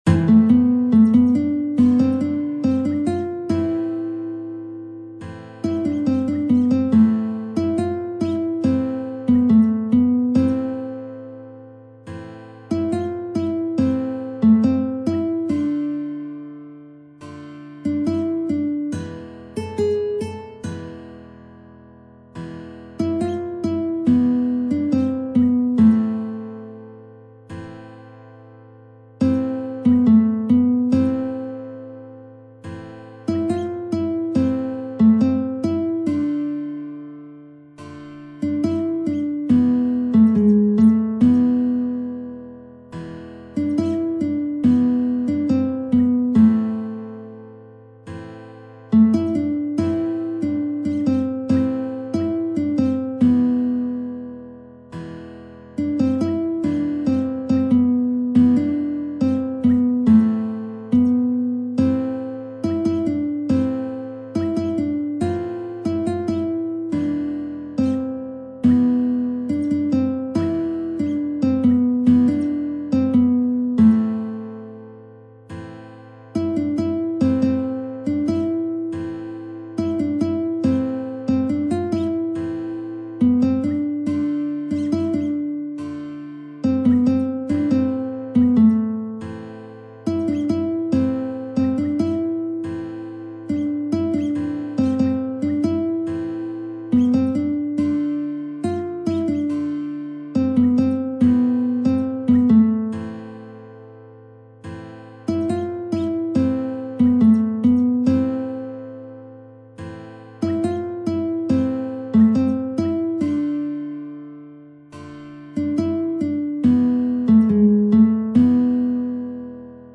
نت ملودی به همراه تبلچر . آکورد